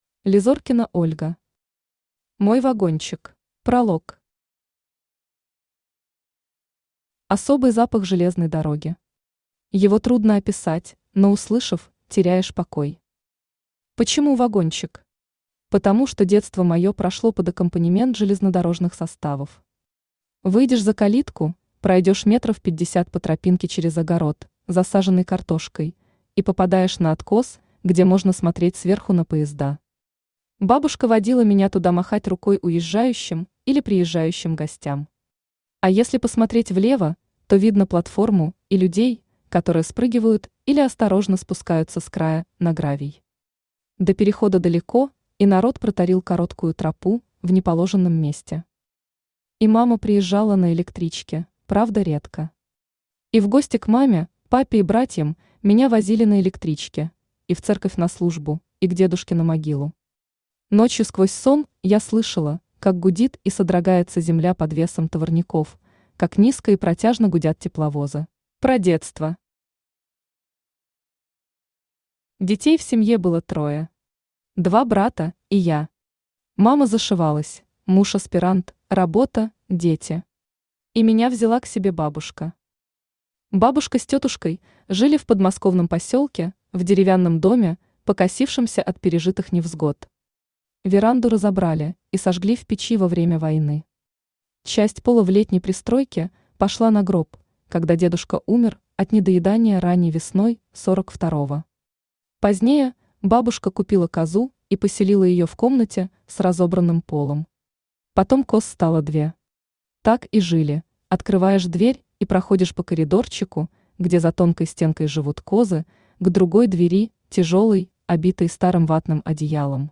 Аудиокнига Мой вагончик | Библиотека аудиокниг